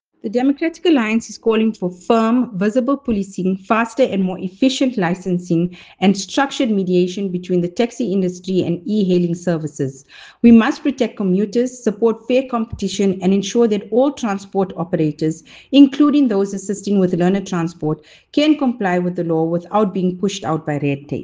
Note to Editors:  Please note Riona Gokool, MPL sound bite in